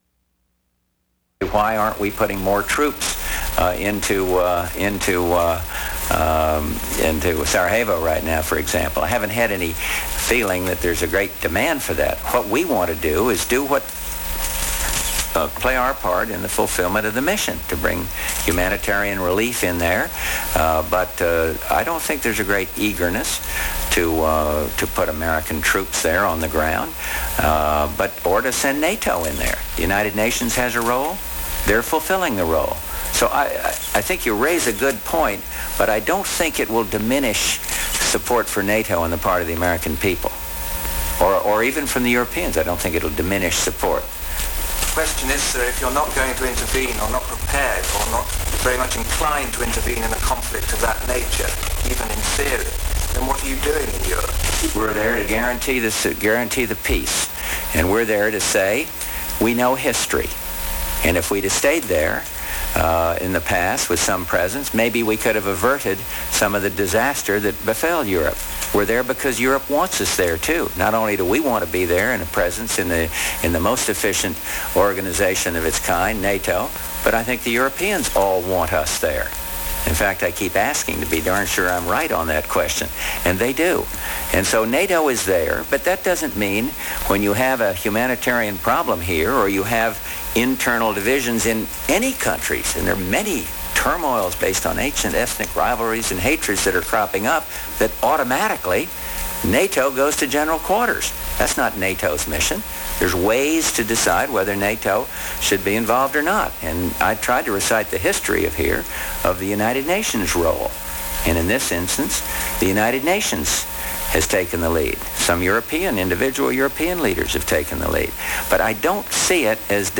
U.S. President George Bush speaks to the international press on the extent and purpose of American and NATO participation in internal conflicts in Yugoslavia